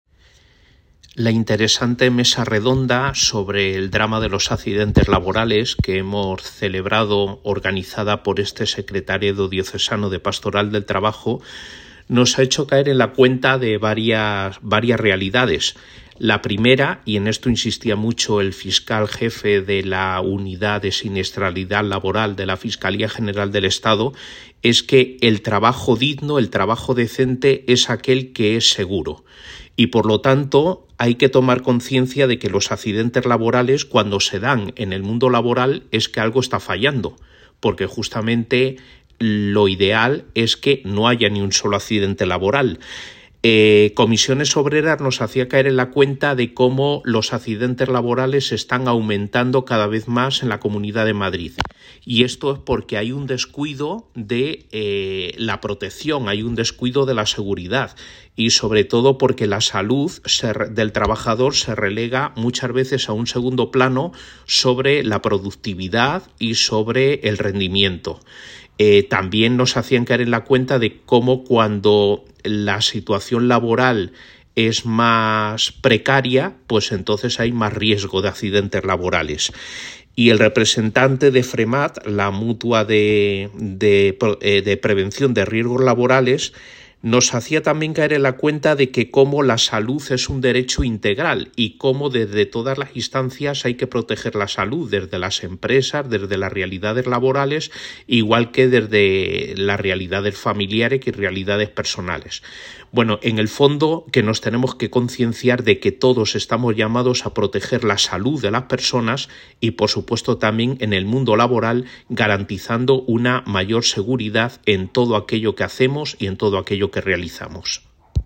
Crónica (audio) breve